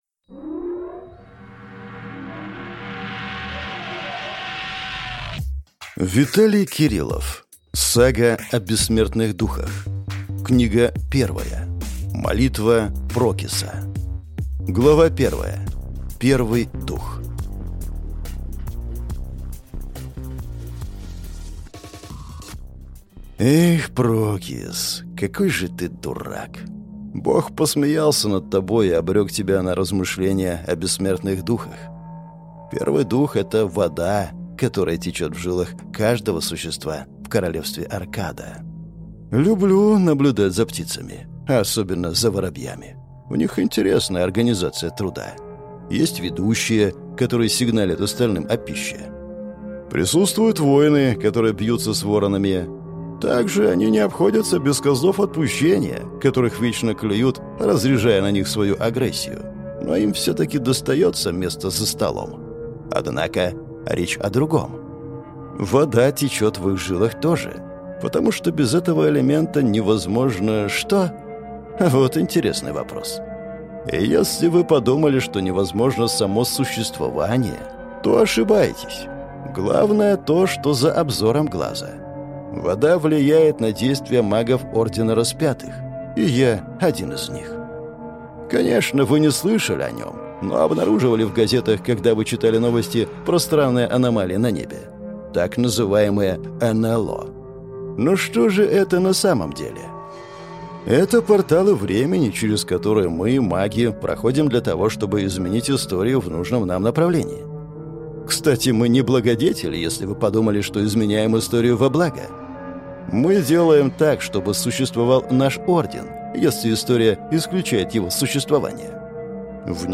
Аудиокнига Сага о бессмертных духах. Книга 1. Молитва Прокиса | Библиотека аудиокниг